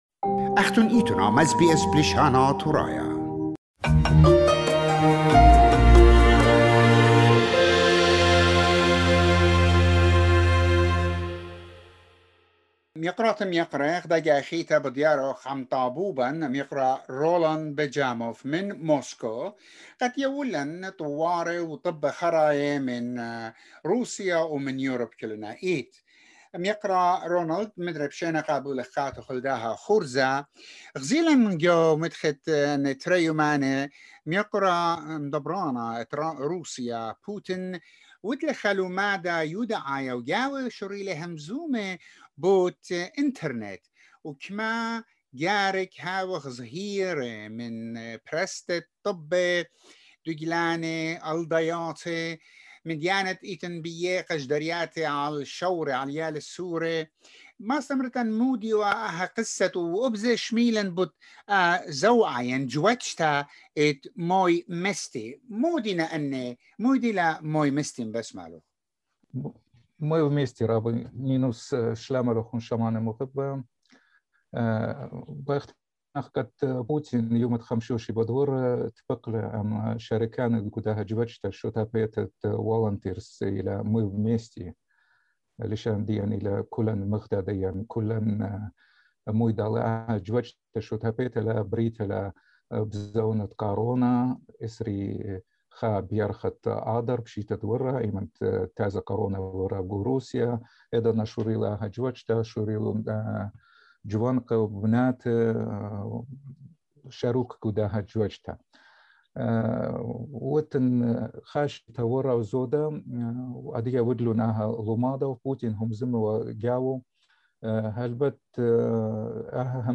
A report